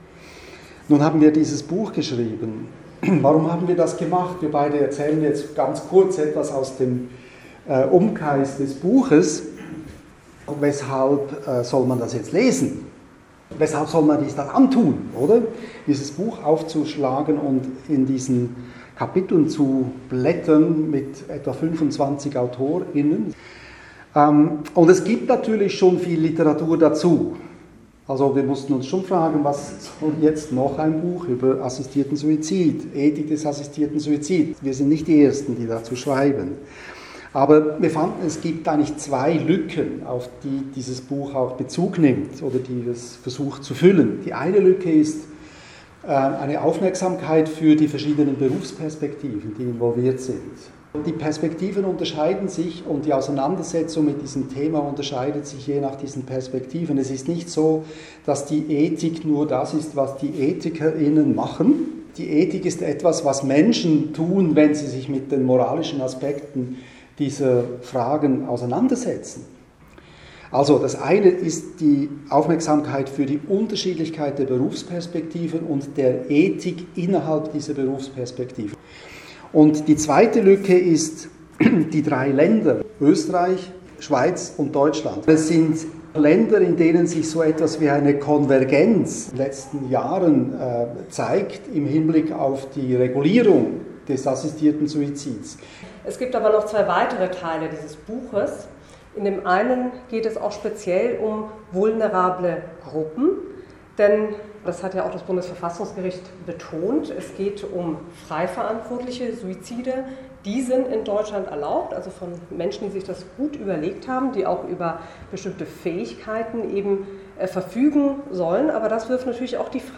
Audio aus der Buchvernissage in Kiel am 11. Oktober 2024.